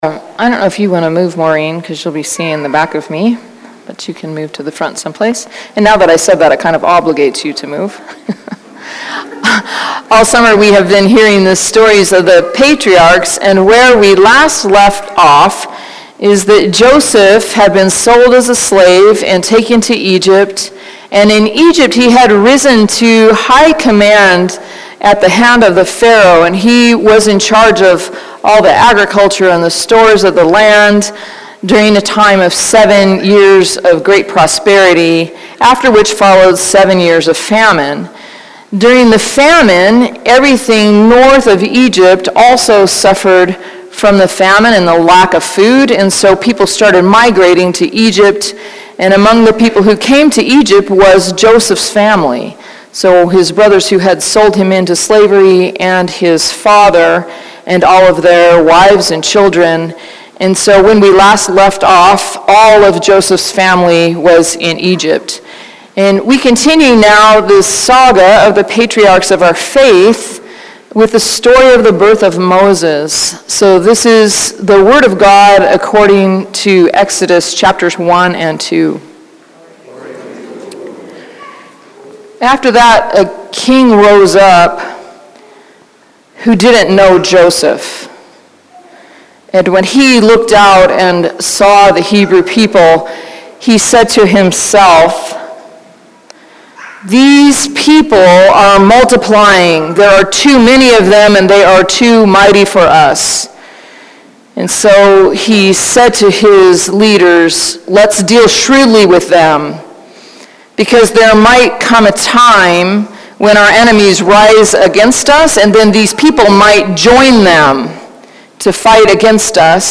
Sermons | Church of the Cross